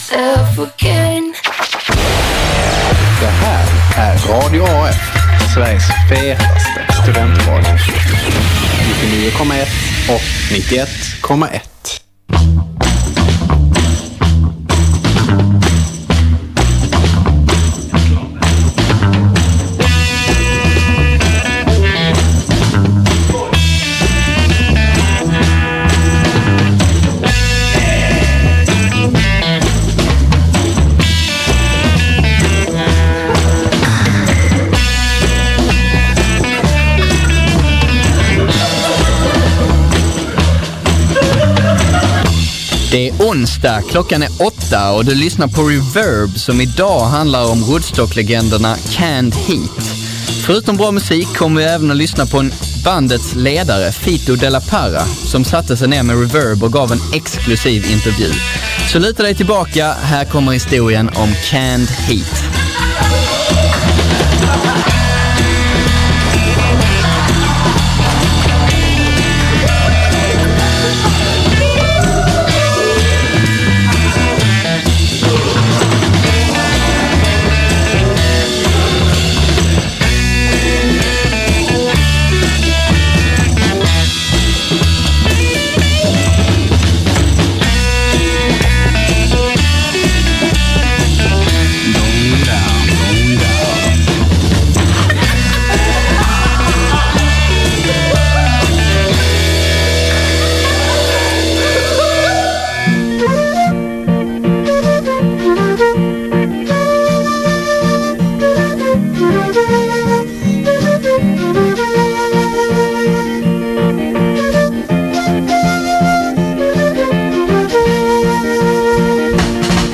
Historien om Canned Heats Bluesliv - Exklusiv intervju med bandledaren Fito de la Parra
I en exklusiv intervju för Reverb berättar bandets trummis och ledare Fito de la Parra om hur han kom med i bandet, varför de inte har tjänat en cent på sin största hit, om oförglömliga konserter, om droger och arresteringar. Det är en historia om musikindustrins skuggsidor och blueslivets vedermödor men också om en livslång passion för musiken och publiken.